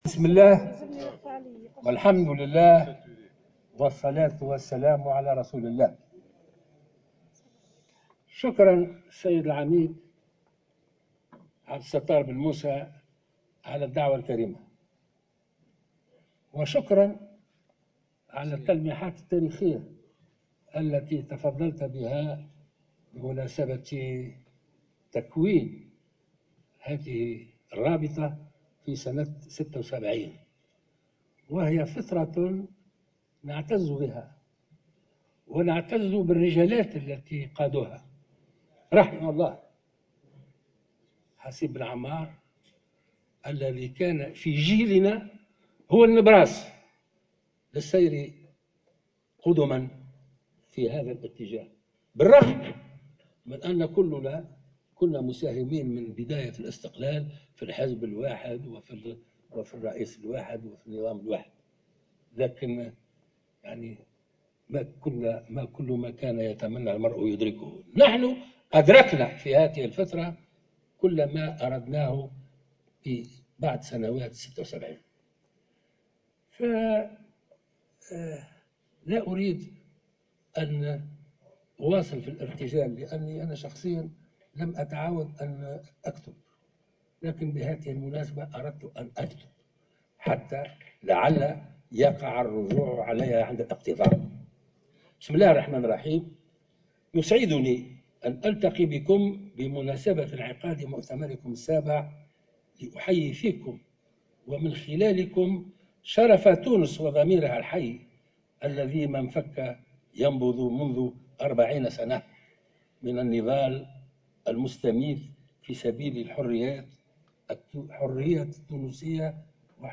كلمة الباجي قائد السبسي